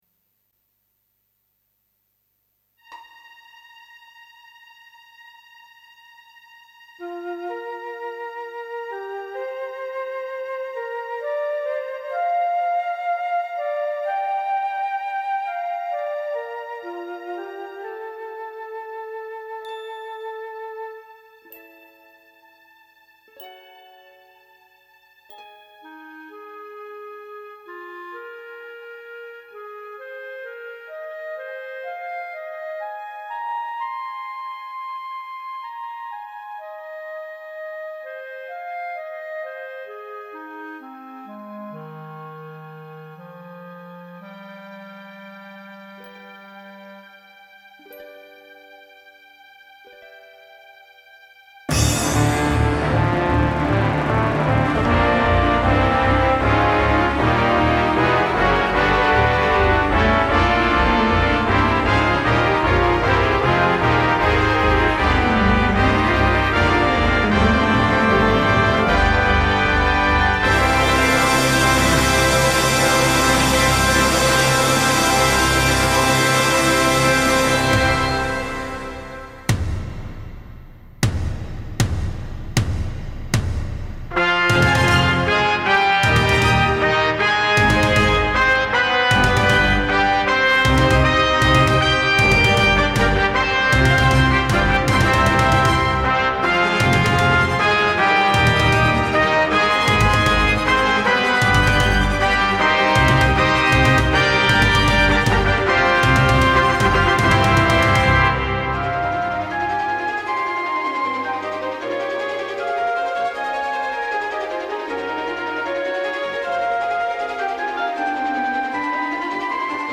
Played by Solo Orchestra